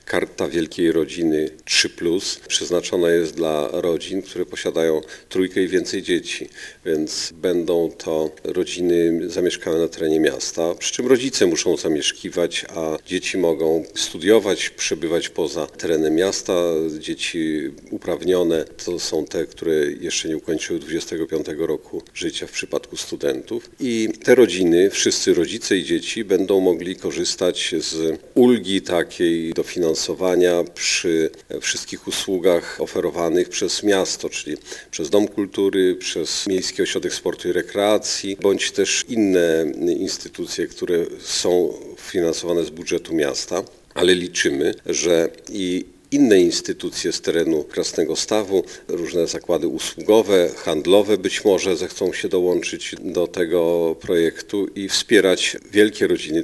Nasz samorząd stawia na rozwój rodziny, na to, aby wspierać małżeństwa, które mają troje i więcej dzieci - mówi burmistrz Krasnegostawu Andrzej Jakubiec.